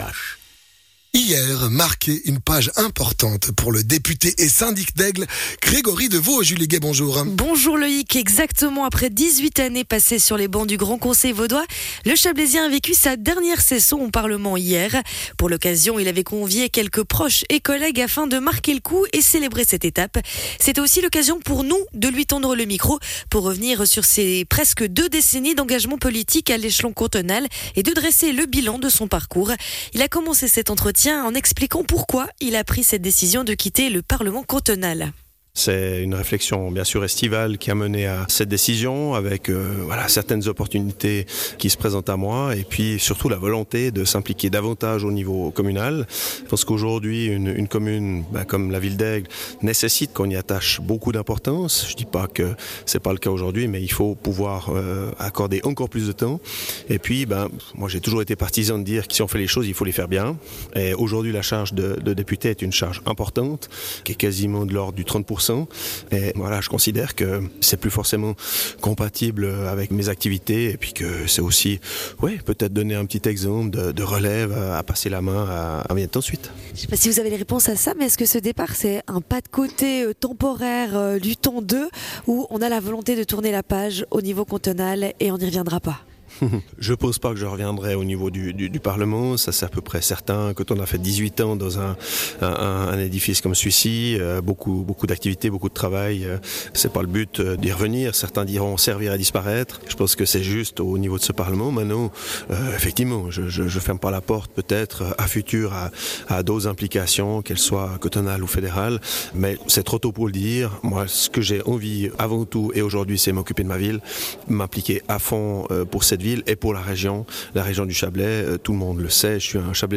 Intervenant(e) : Grégory Devaud, Philippe Leuba